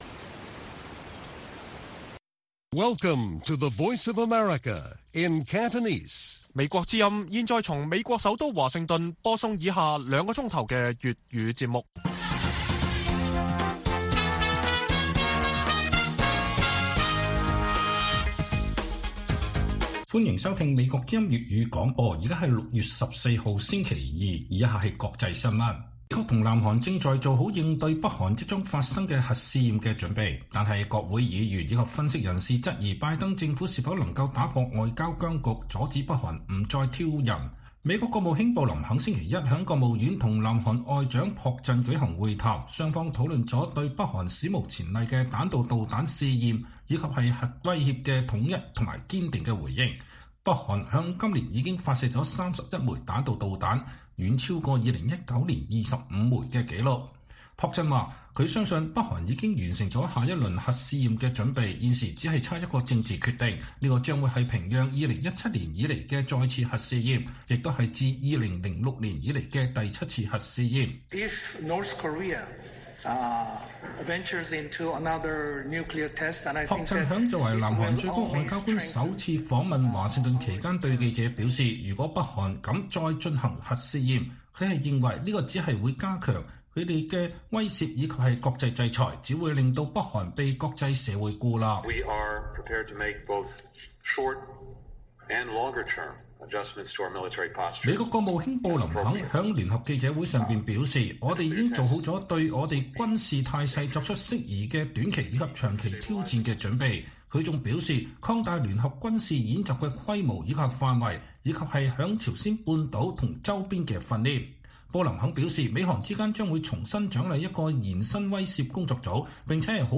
粵語新聞 晚上9-10點 : 香港民研：40%支持平反六四創20年新低 前區議員指市民對中國政府沒期望